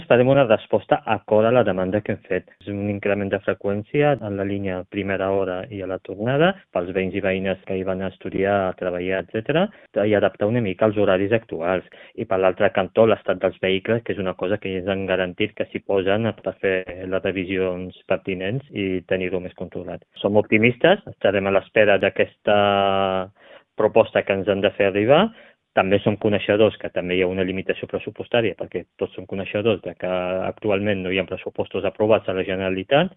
El tinent d’Alcaldia de Mobilitat, Soufian Laroussi, ha explicat a Ràdio Calella TV que la Generalitat ja coneixia la situació i ha assumit el compromís de treballar-hi.